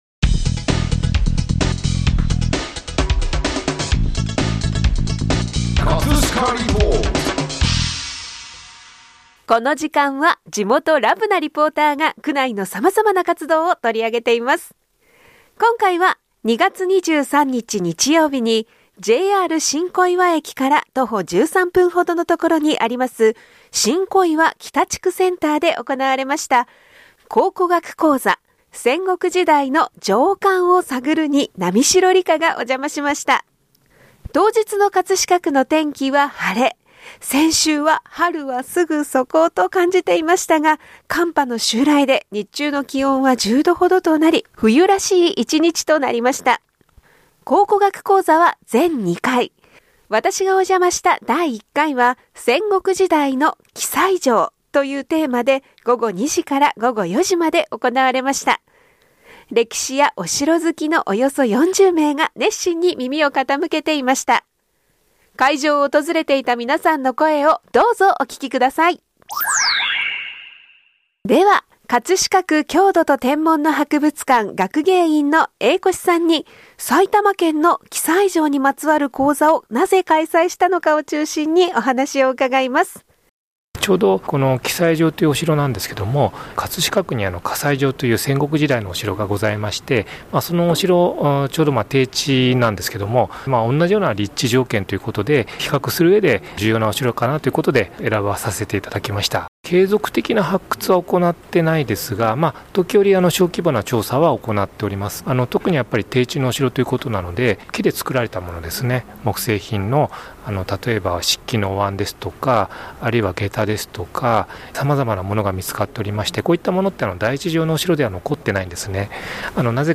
【葛飾リポート】 今回は2月23日㈰にJR新小岩駅から徒歩13分のところにあります新小岩北地区センターで行わ…
歴史やお城好きのおよそ40名が熱心に耳を傾けていました。 ＜参考写真 葛飾の葛西城跡（御殿山公園・葛西城址公園）＞ 会場を訪れていた皆さんの声をどうぞお聞きください！